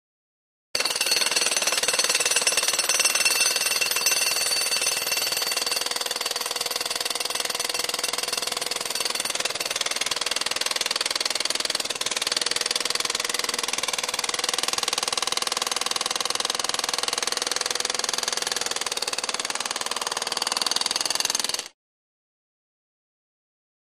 JackHammerAtWork EE157001
Jack Hammer; Jack Hammer At Work.